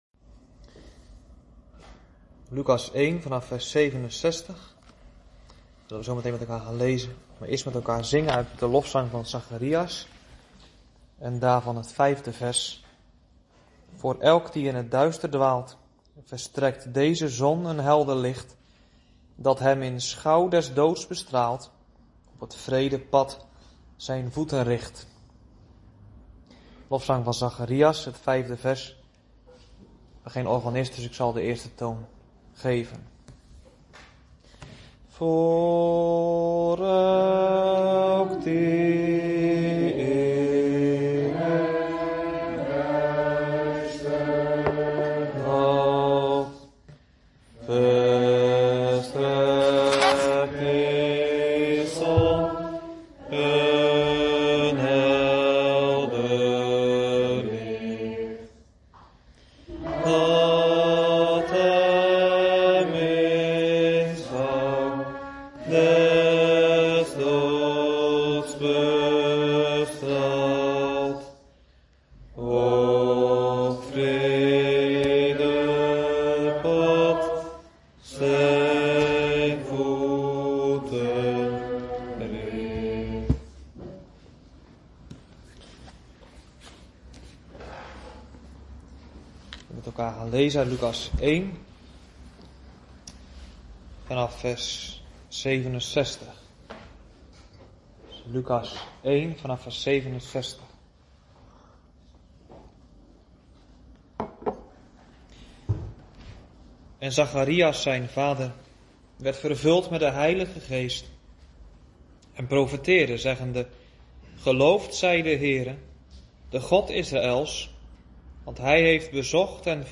Lezing-33-Dordtse-Leerregels-H5-artikel-3-Hoe-God-de-volharding-werkt.mp3